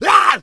healer_pain2.wav